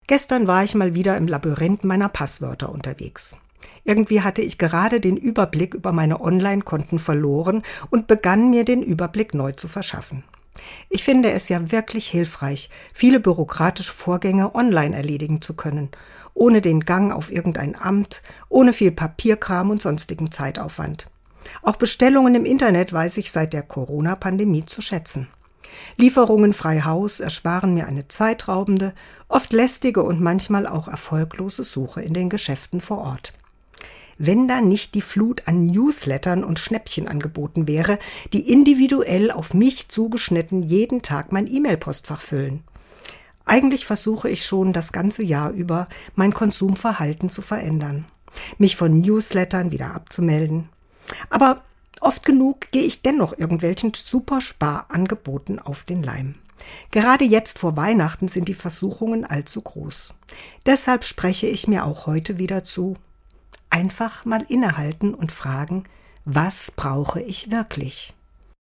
Dezember 2024, Autorin und Sprecherin ist